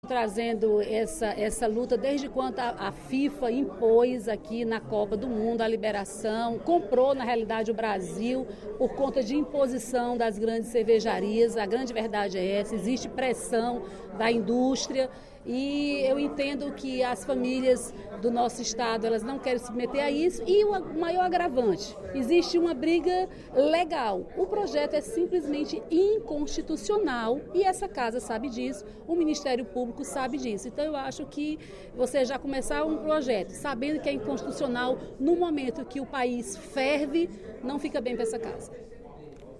A deputada Dra. Silvana (PMDB) afirmou, no primeiro expediente da sessão plenária desta quarta-feira (24/05), ser contra dois projetos de lei, que tramitam na Assembleia, solicitando a liberação da venda de bebidas alcoólicas em estádios de futebol.